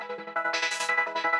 SaS_MovingPad04_170-E.wav